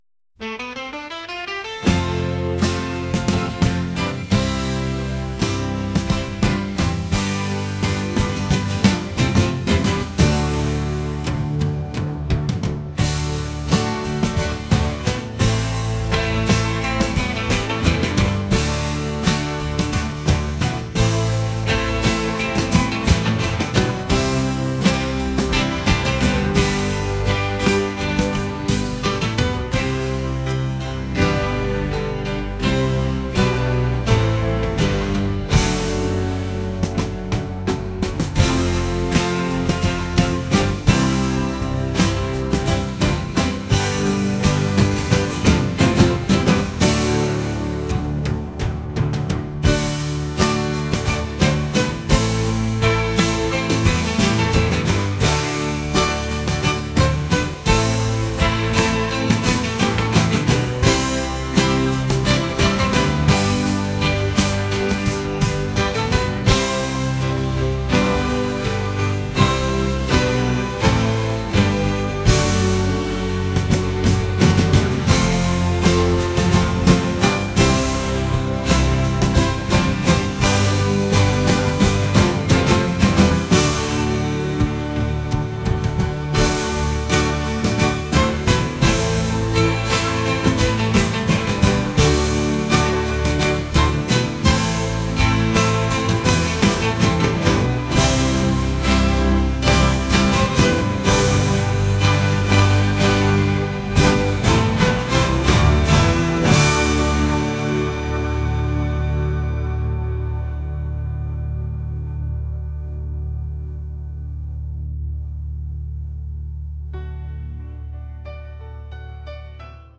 rock | indie | cinematic